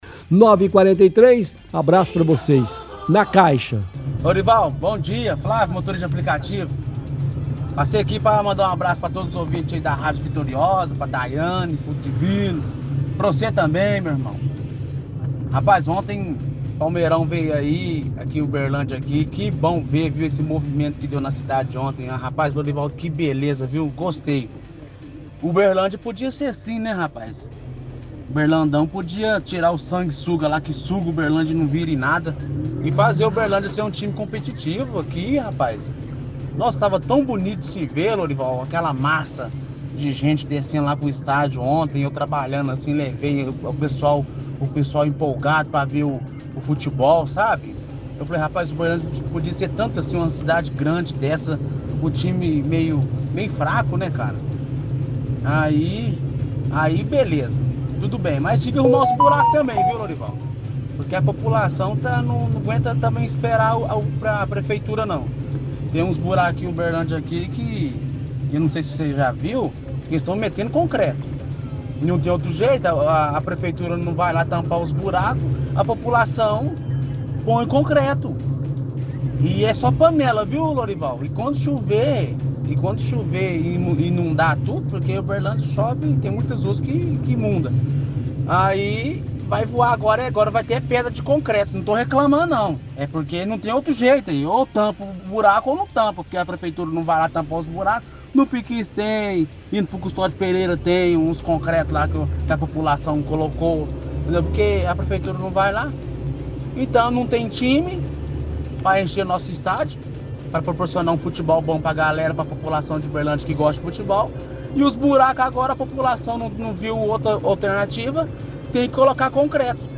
– Ouvinte diz que a população está colocando concreto nos buracos das ruas já que a prefeitura não faz a manutenção.